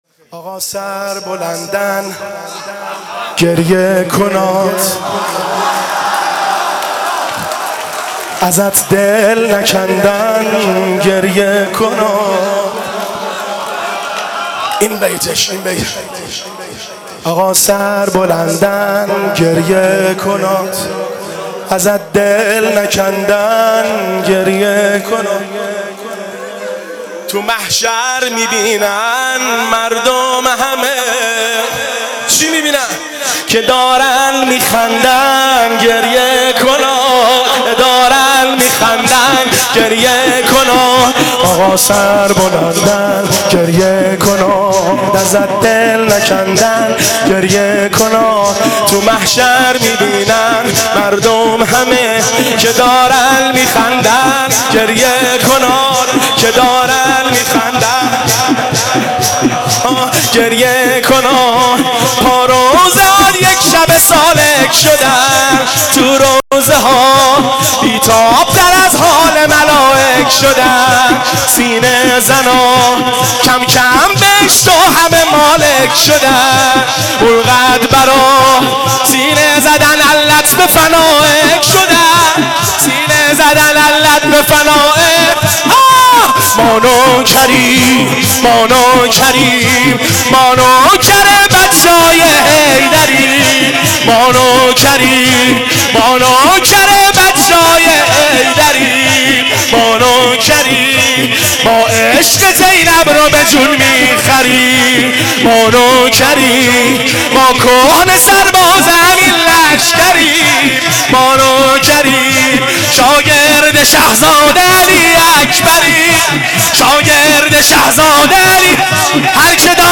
مداح
قالب : شور